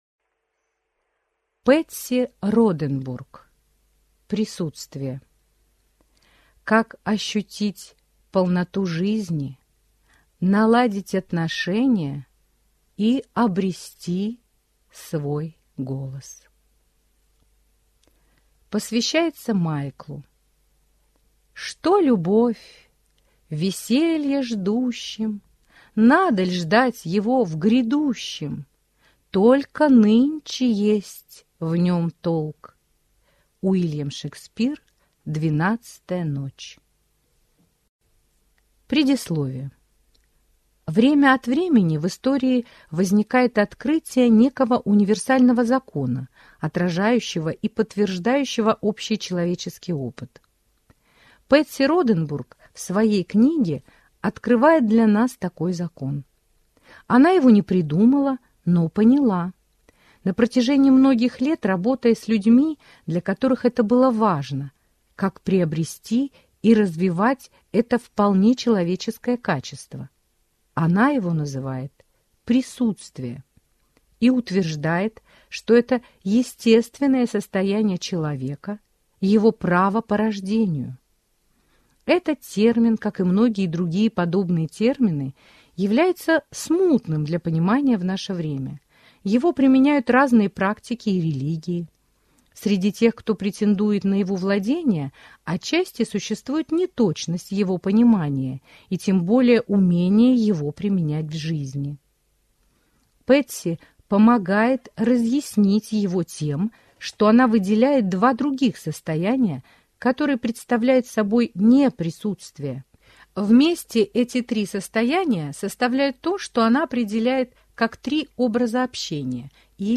Аудиокнига Присутствие. Как ощутить полноту жизни, наладить отношения и обрести свой голос | Библиотека аудиокниг